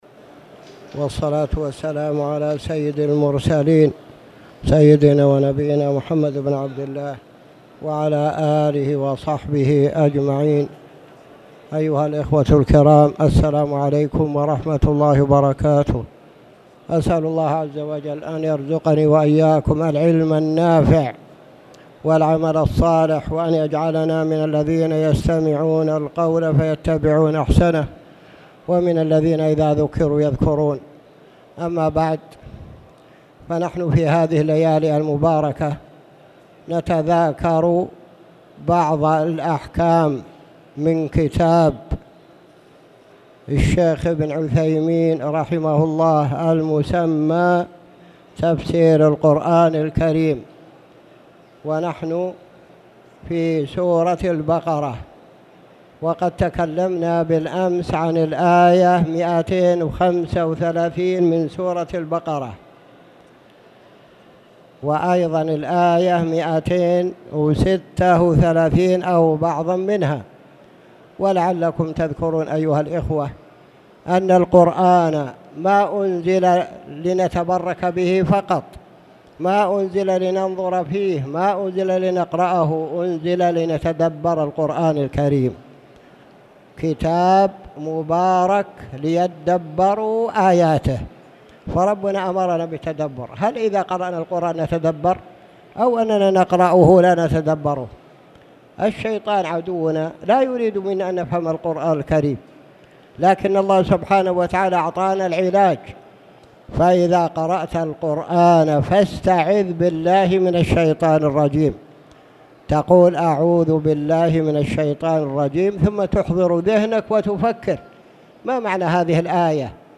تاريخ النشر ٢٥ ذو الحجة ١٤٣٧ هـ المكان: المسجد الحرام الشيخ